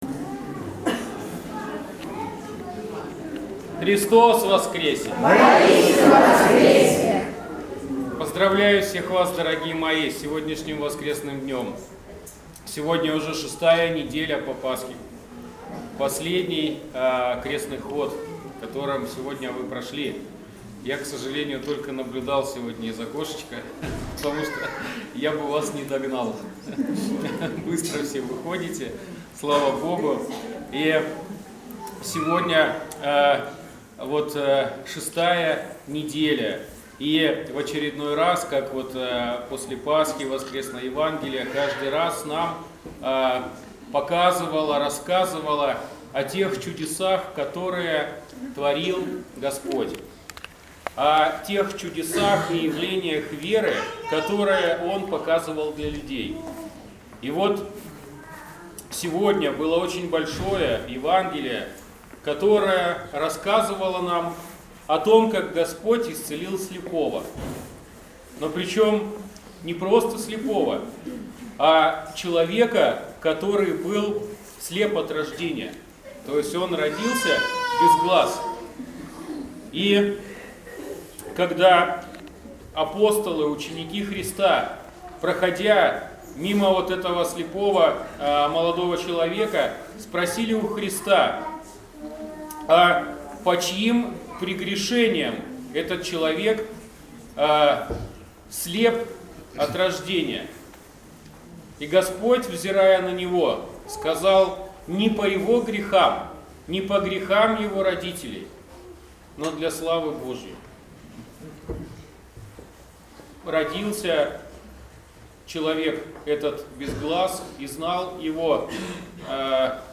Воскресная проповедь